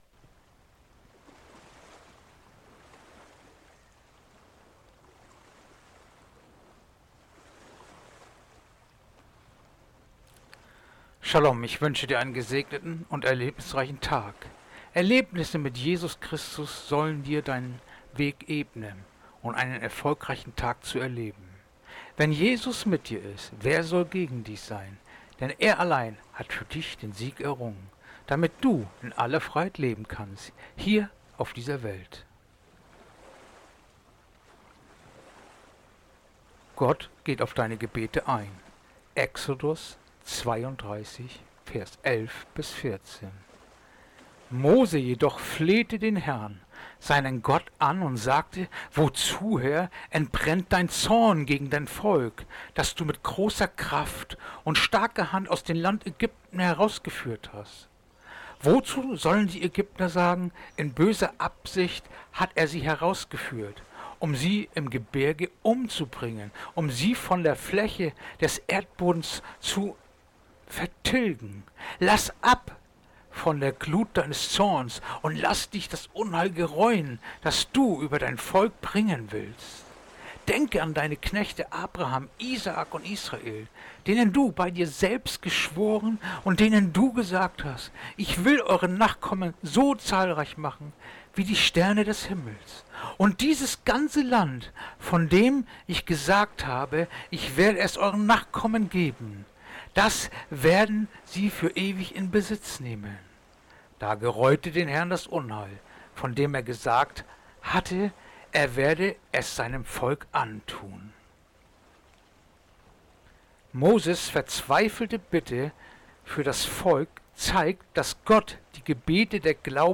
Andacht-vom-08.-September-Exodus-32-11-14